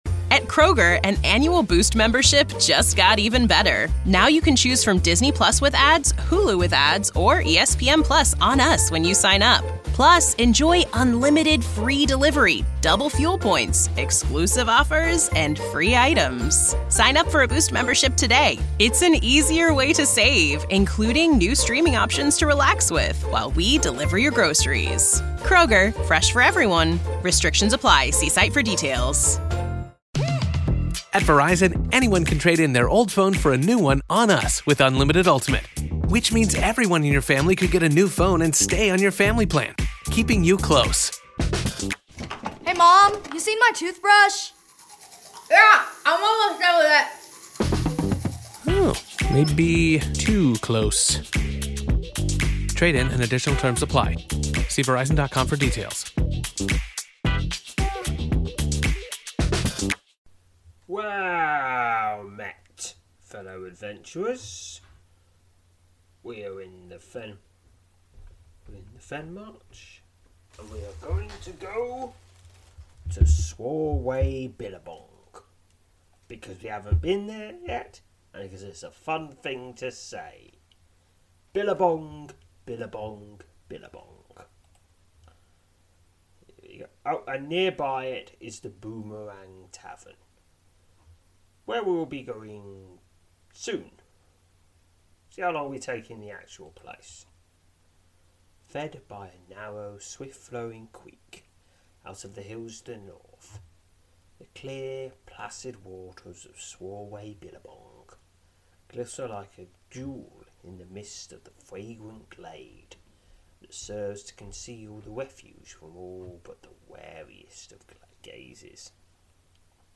Audio Drama